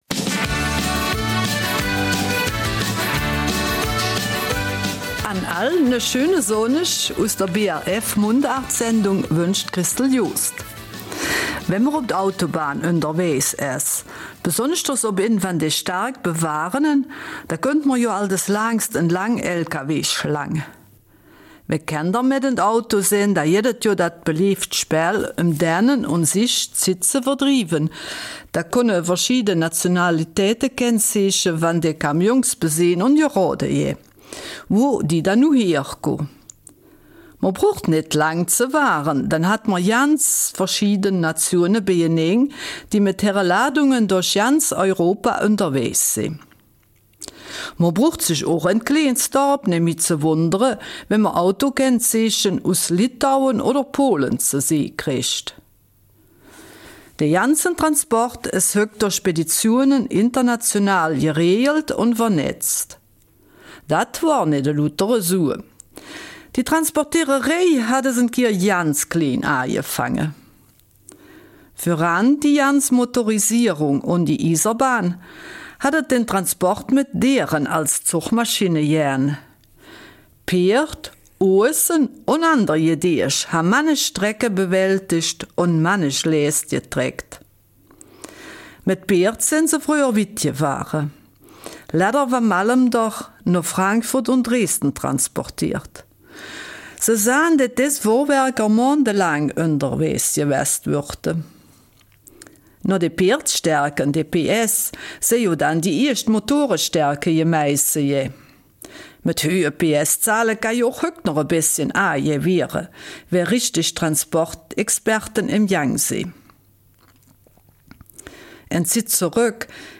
Eifeler Mundart: Transportmittel vor der Motorisierung und der Eisenbahn